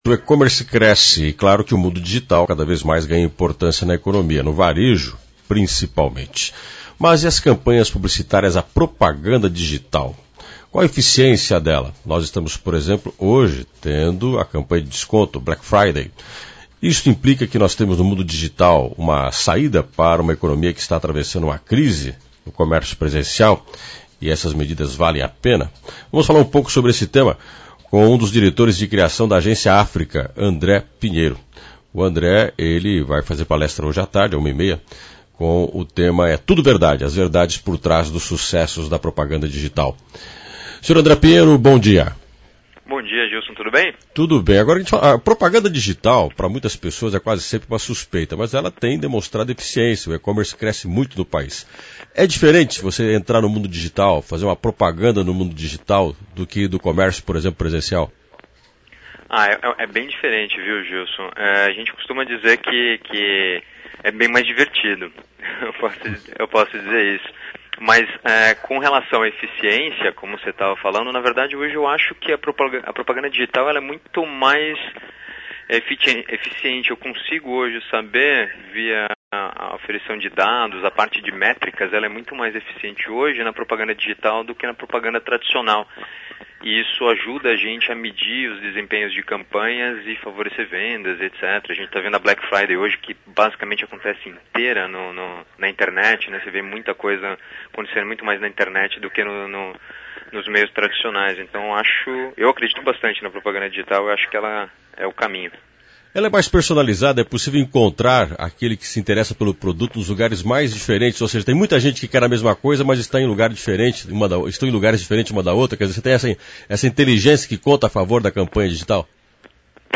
entrevista_0.mp3